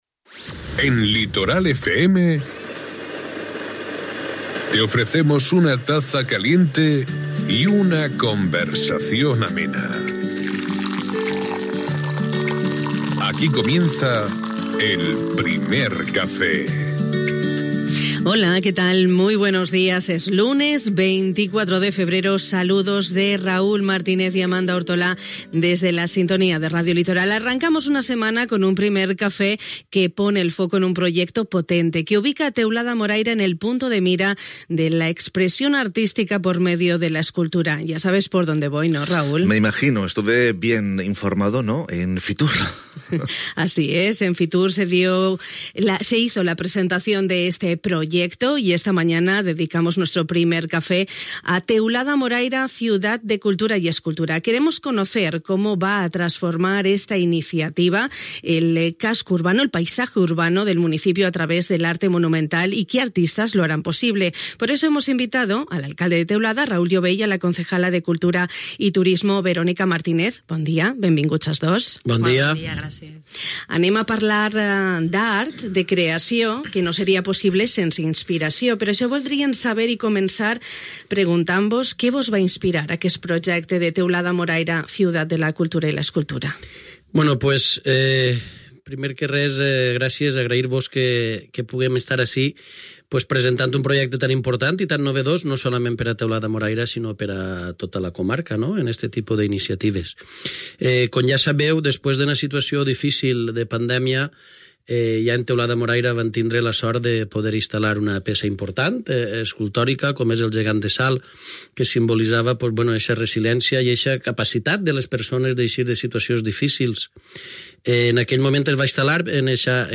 Al Primer Cafè de Ràdio Litoral hem començat la setmana posant el focus en un projecte potent que situarà a Teulada Moraira al punt de mira de l'expressió artística per mitjà de l'escultura. Aquesta matí hem conegut una iniciativa única i nova: Teulada Moraira Ciutat de Cultura i Escultura, amb l'alcalde de la localitat, Raúl Llobell i la regidora de Cultura i Turisme, Verónica Martínez.